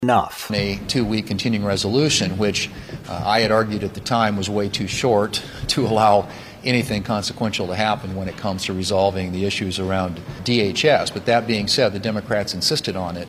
Thune argued last week the original one wasn’t enough…